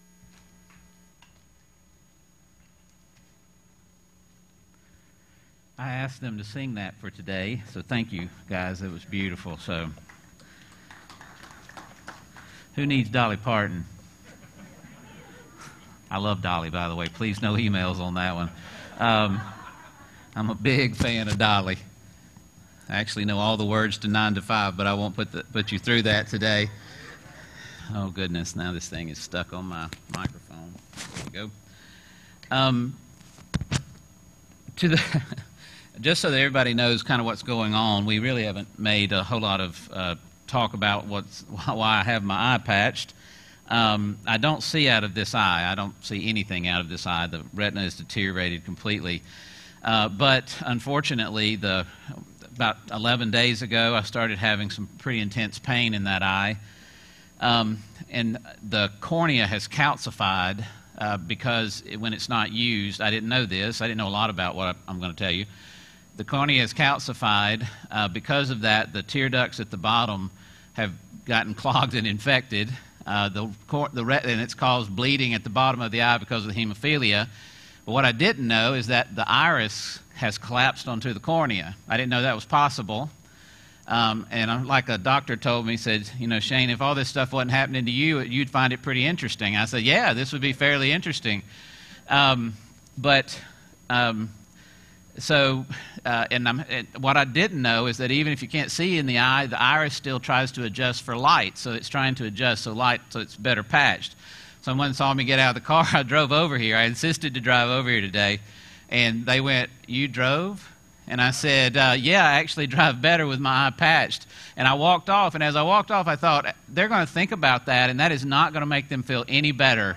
A message from the series "Liar, Lunatic, or Lord."